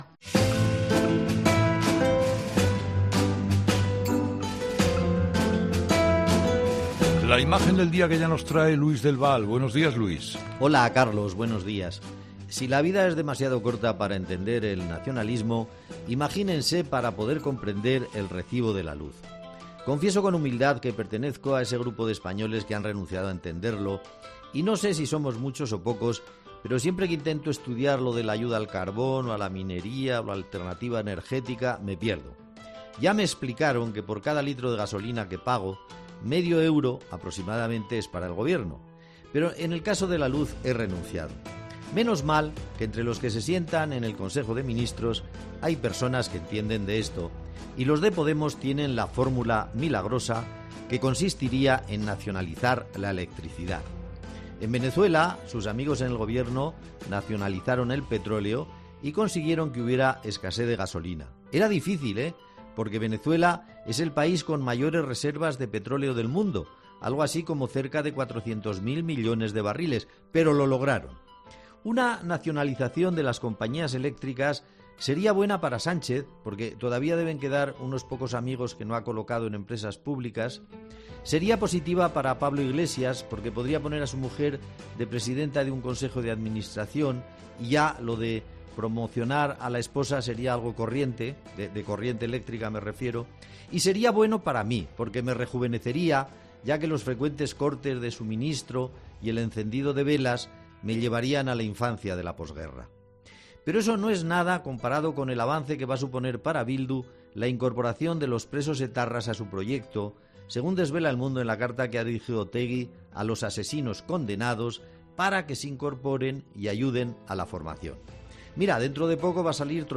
Luis del Val pone el foco de la imagen del día de "Herrera en COPE" en la subida del precio de la luz.